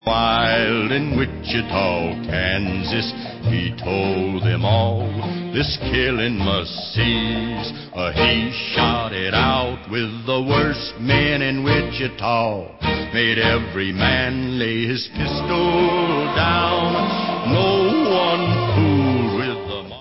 Western soundtracks